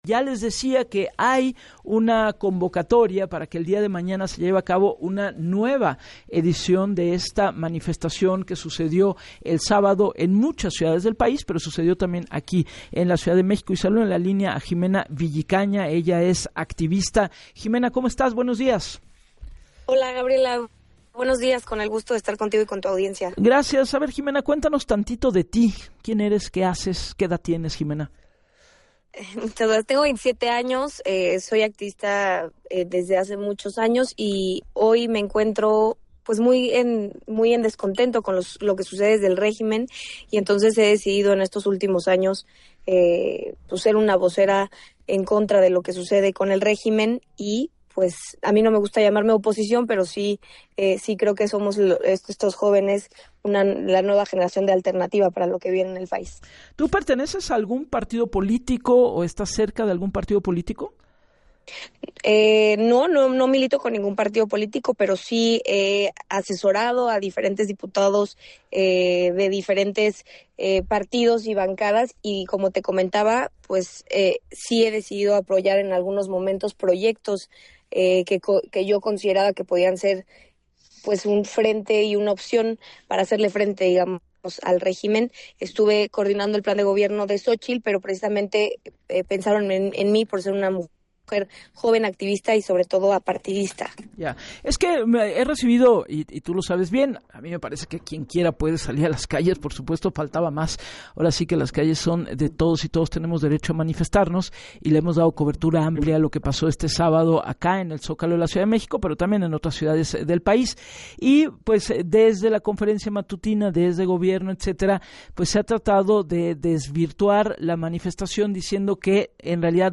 En entrevista con Gabriela Warkentin